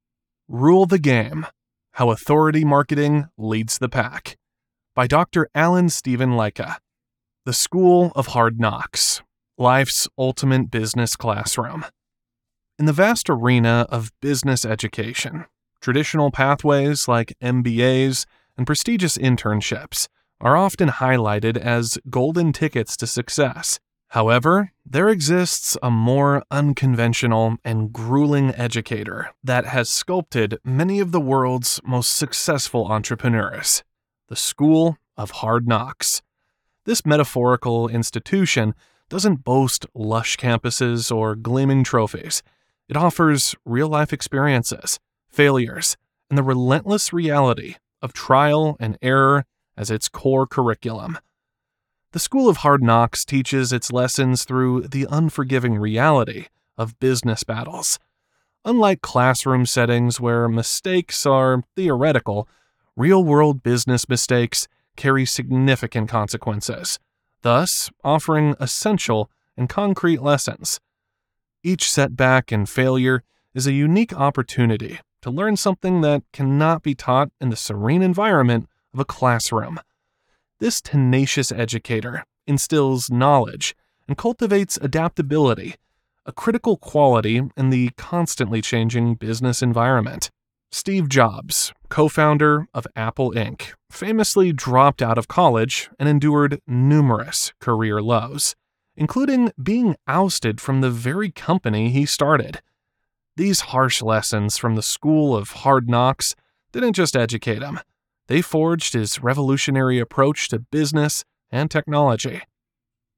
Audio Book Voice Over Narrators
Yng Adult (18-29) | Adult (30-50)